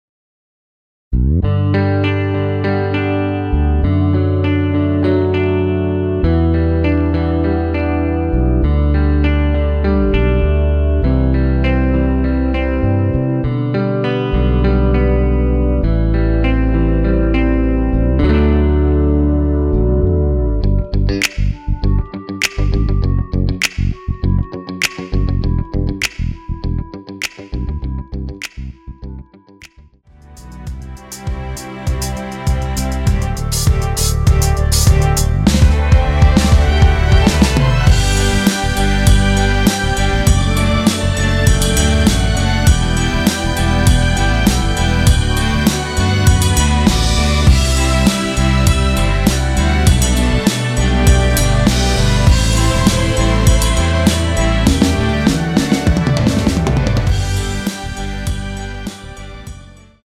원키에서(-2)내린 멜로디 포함된 MR입니다.(미리듣기 확인)
앞부분30초, 뒷부분30초씩 편집해서 올려 드리고 있습니다.
(멜로디 MR)은 가이드 멜로디가 포함된 MR 입니다.